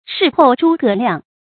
事后诸葛亮 shì hòu zhū gé liàng
事后诸葛亮发音